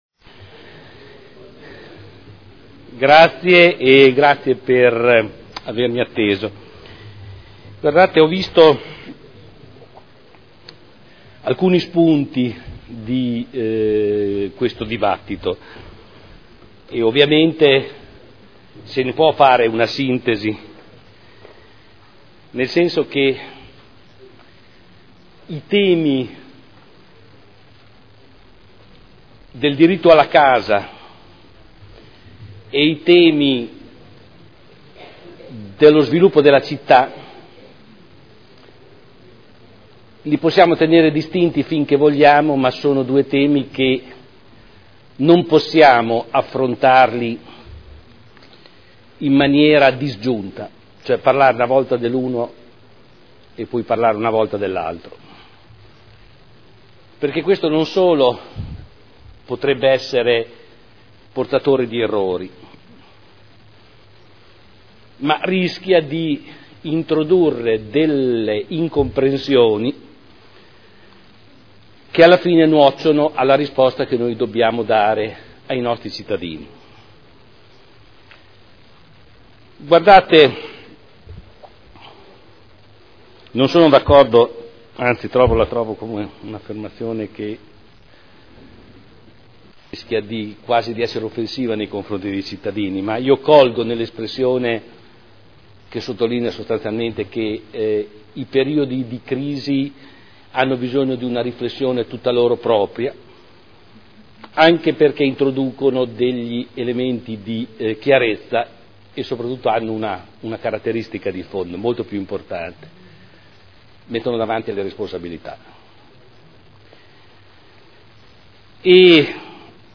Seduta del 19 marzo Proposta di deliberazione Programma per l’edilizia sociale – Principi ed indirizzi – Per un nuovo piano abitativo sociale (Qualità – Sostenibilità – Equità) Dibattito